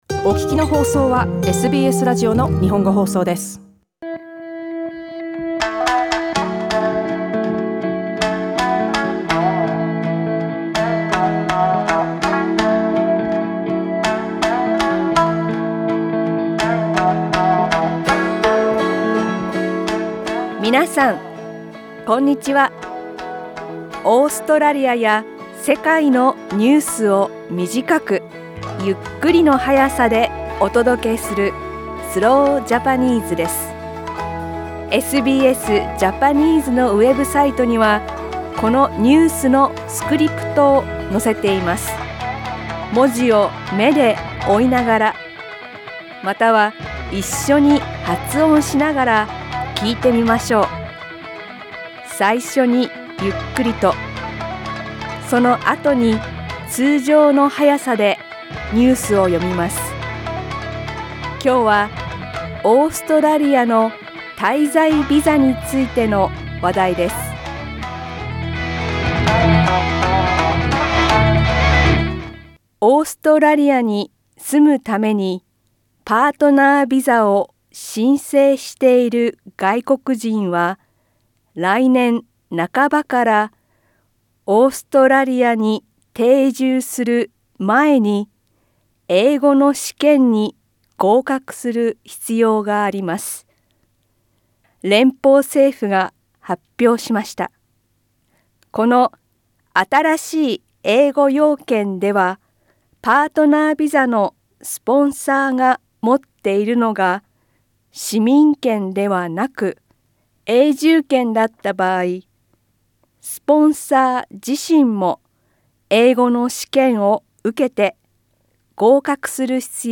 Short Australian/World news read aloud slowly in Japanese.